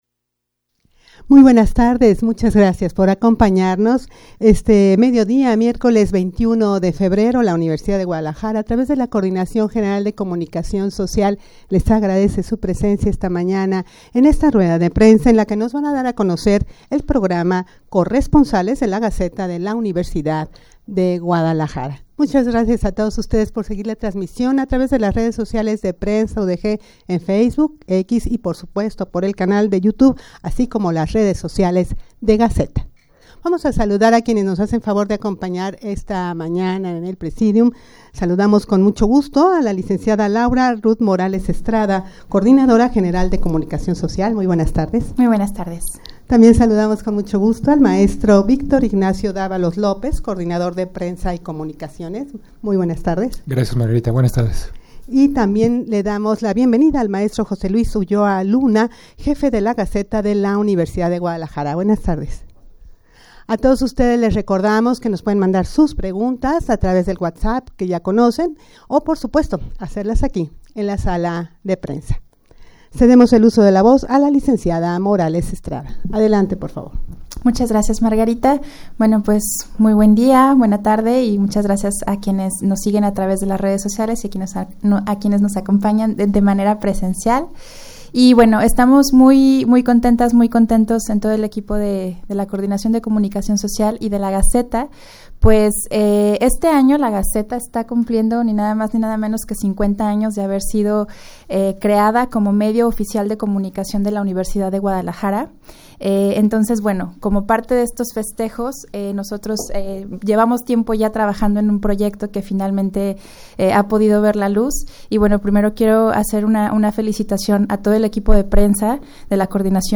Audio de la Rueda de Prensa
rueda-de-prensa-para-dar-a-conocer-el-programa-corresponsal-gaceta-udeg.mp3